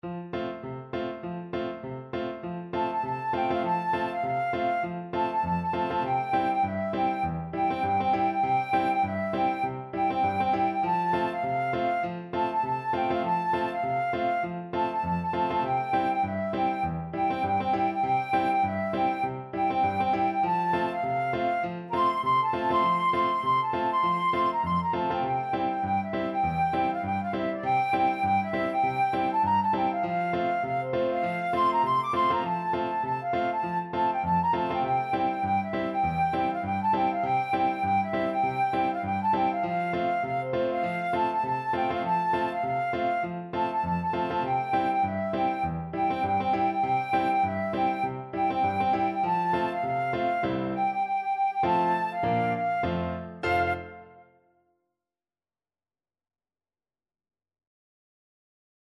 Flute
Two in a bar with a light swing =c.100
Traditional (View more Traditional Flute Music)
tit_galop_FL.mp3